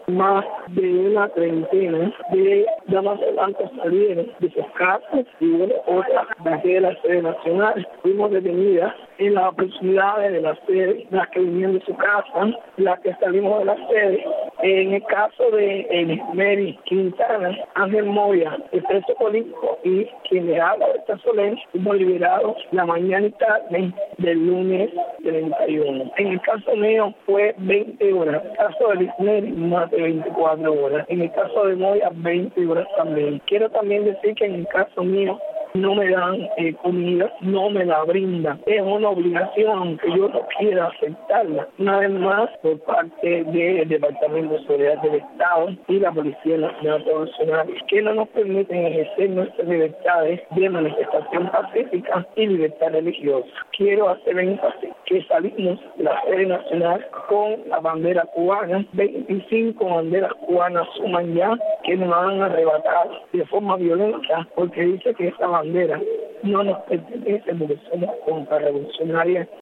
Berta Soler explicó a Martí Noticias las circunstancias de la detención el pasado domingo.
En declaraciones a Radio Martí, Berta Soler explicó que en realidad fueron arrestados una treintena de activistas.